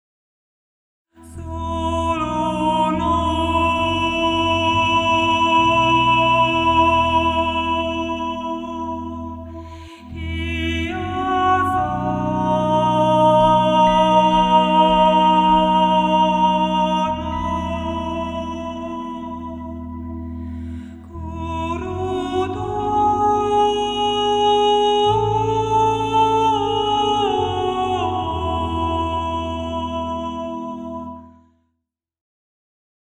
Meditative / Poesie
Momentum-Aufnahmen